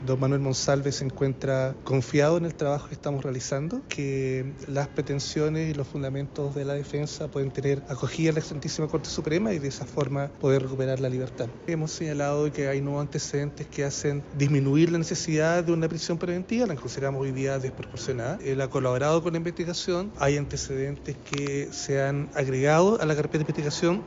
En tanto, el abogado defensor de Monsalve, el jefe de la Defensoría Penal Pública de Santiago, Victor Providel, señaló que la exautoridad se encuentra confiada del trabajo realizado e insistió en la desproporcionalidad de la medida cautelar más gravosa.